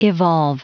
Prononciation du mot evolve en anglais (fichier audio)
Prononciation du mot : evolve